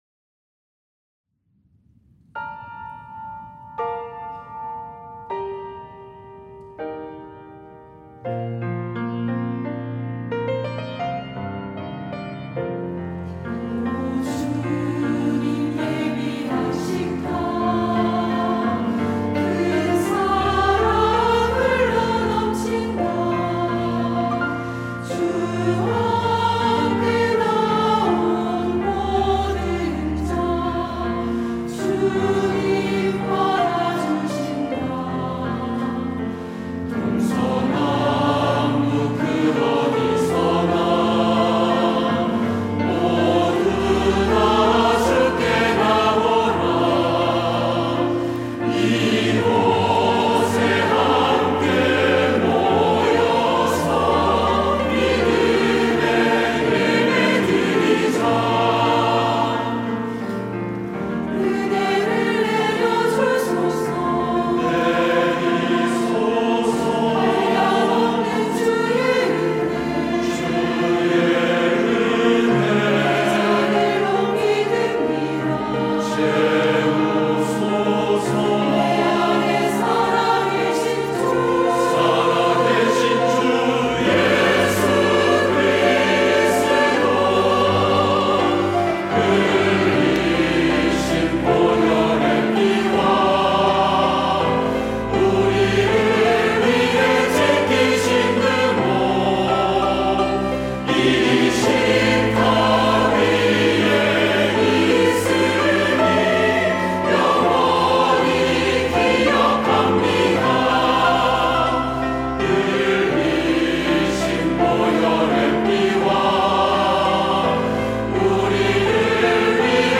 할렐루야(주일2부) - 오 주님 예비하신 식탁
찬양대